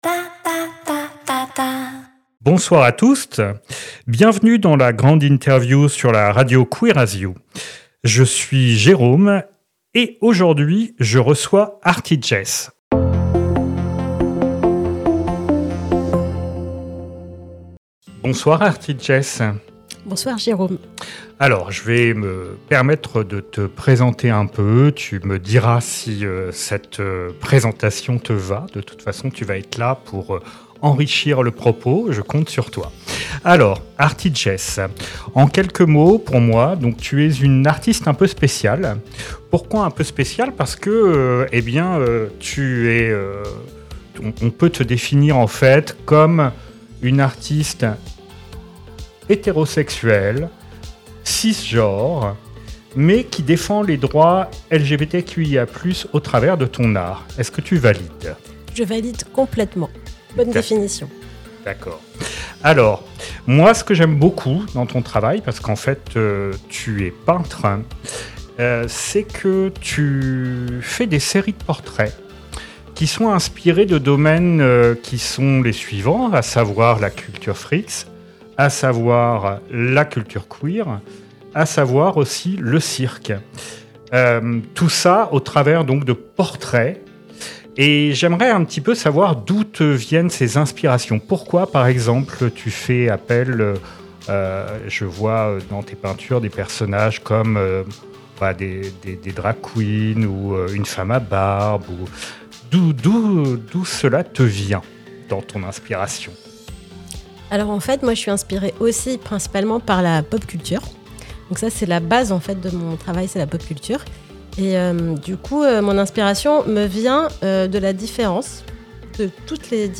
lgi-la-grande-interview-saison-02-episode-04-la-radio-queer-as-you.mp3